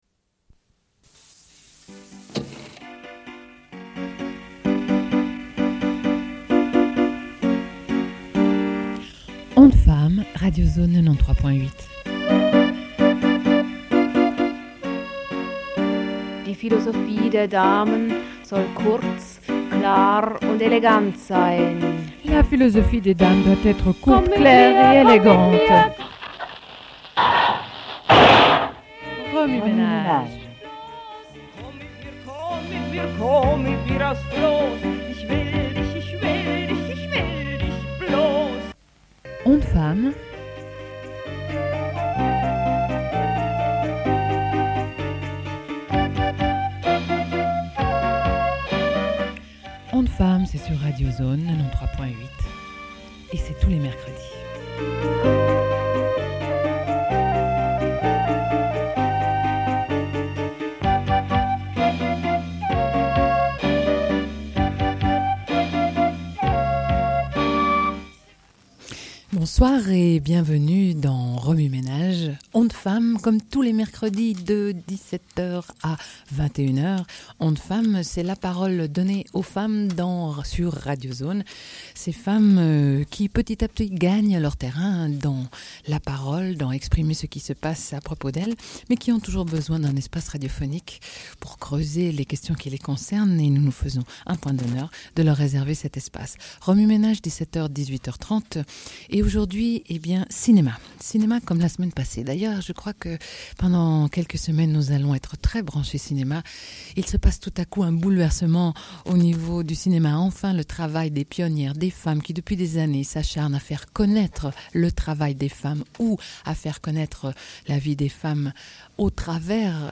Une cassette audio, face A31:23
Radio